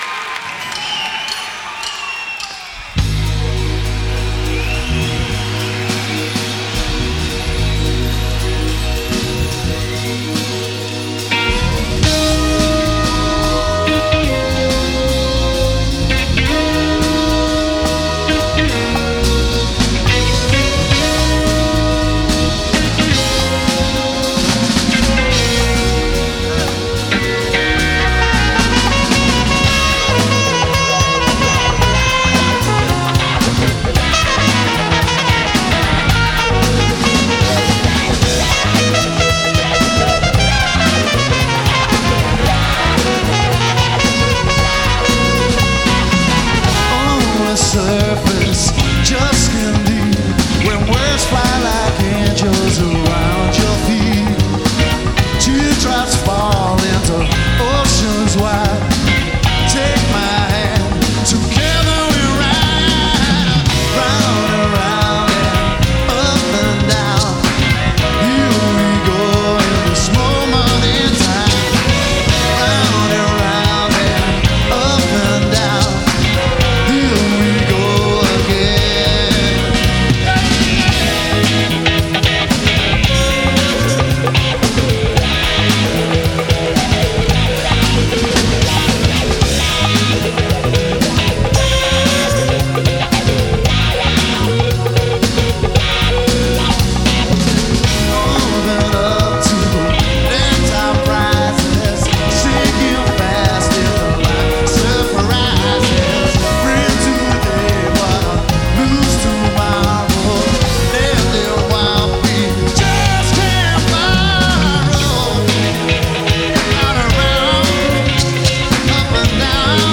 Town And Country Live Concert 5_12_1990